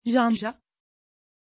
Pronunciation of Janja
pronunciation-janja-tr.mp3